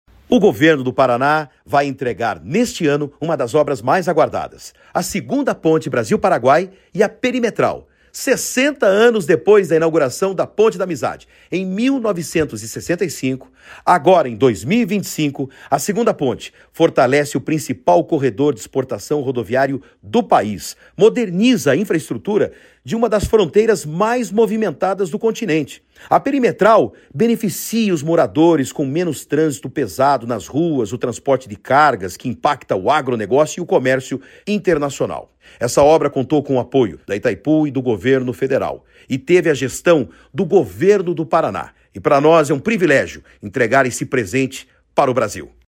Sonora do secretário de Infraestrutura e Logística, Sandro Alex, sobre o avanço das obras da Perimetral Leste de Foz do Iguaçu